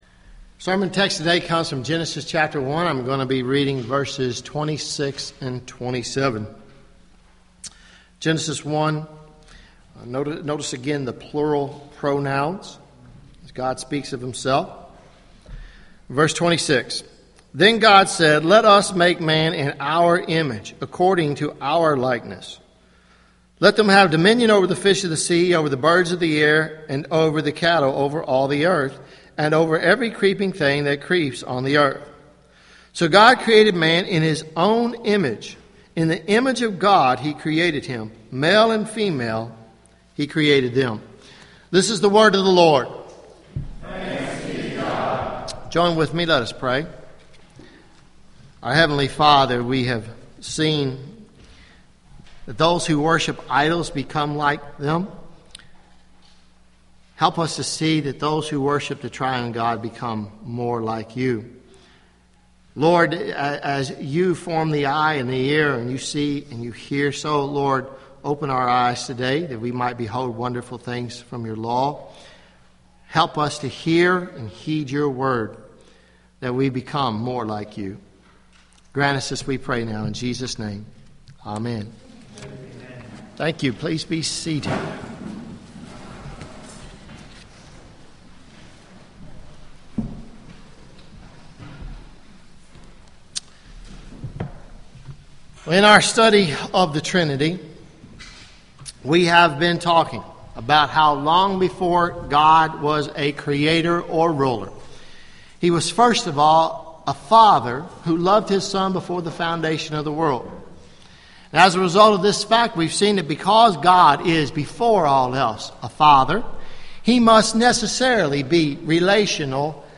Sermons May 05 2013 Preached May 5